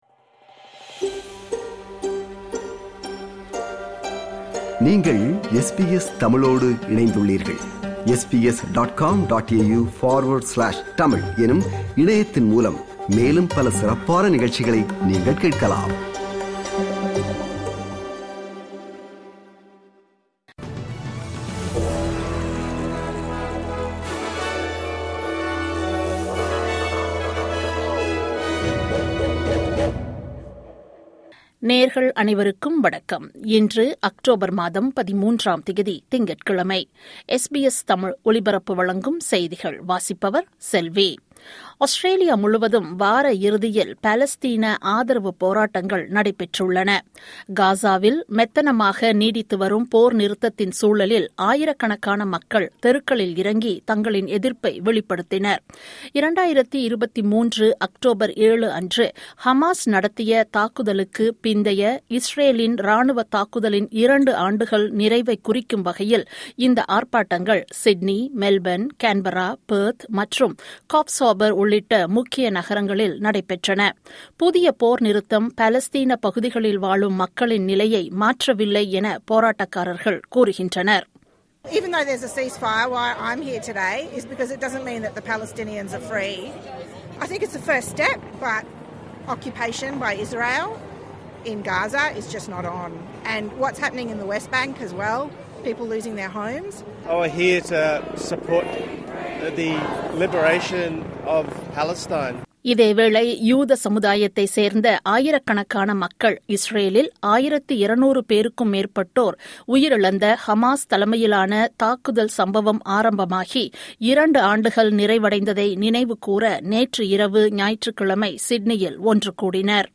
இன்றைய செய்திகள்: 13 அக்டோபர் 2025 திங்கட்கிழமை
SBS தமிழ் ஒலிபரப்பின் இன்றைய (திங்கட்கிழமை 13/10/2025) செய்திகள்.